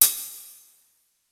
Percusión 6: platillo 1
platillo
idiófono
percusión
golpe